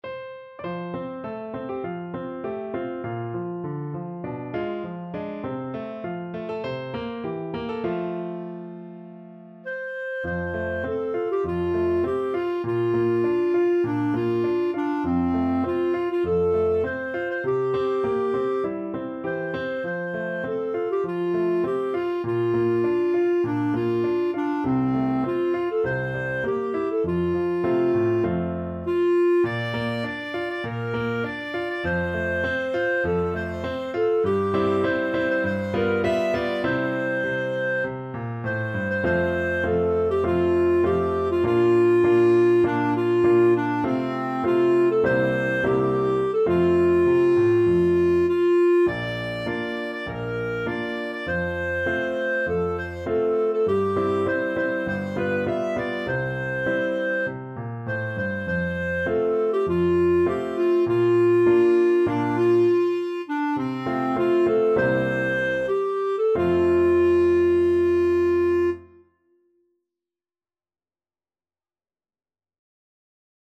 4/4 (View more 4/4 Music)
~ = 100 Moderato